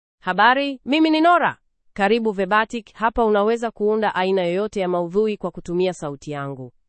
FemaleSwahili (Kenya)
NoraFemale Swahili AI voice
Nora is a female AI voice for Swahili (Kenya).
Voice sample
Nora delivers clear pronunciation with authentic Kenya Swahili intonation, making your content sound professionally produced.